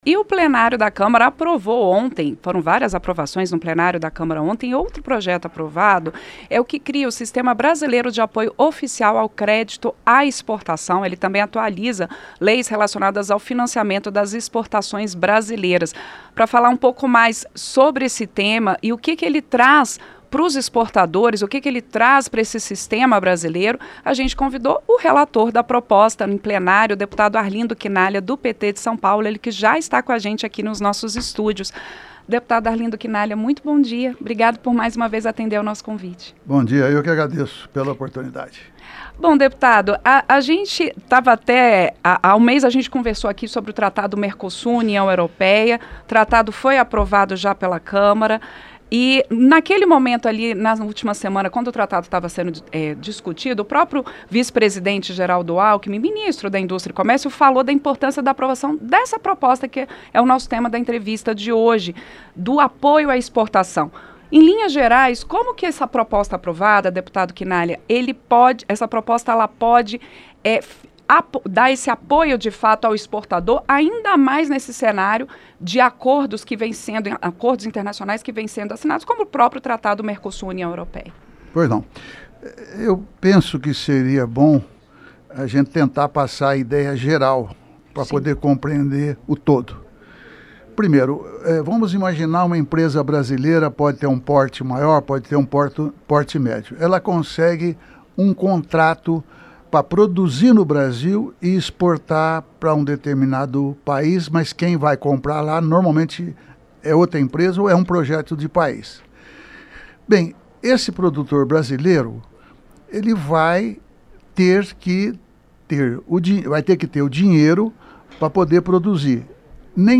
Entrevista - Dep. Arlindo Chinaglia (PT-SP)